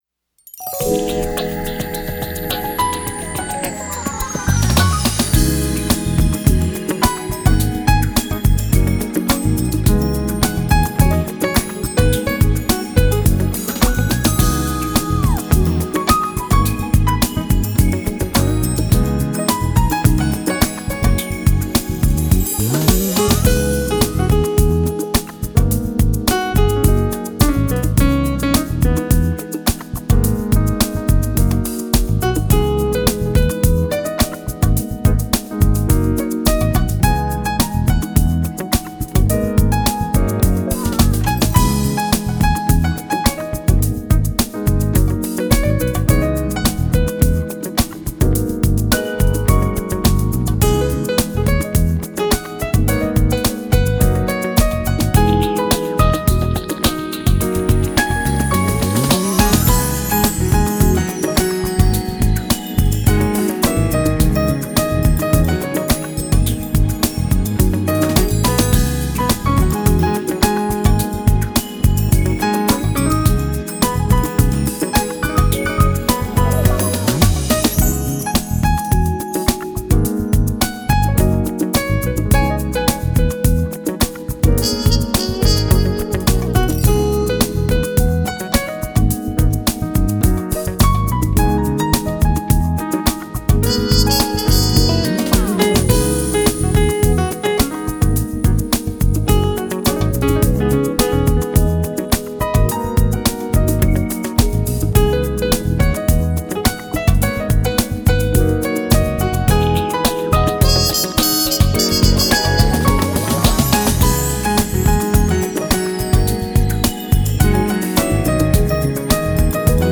Genre: Smooth Jazz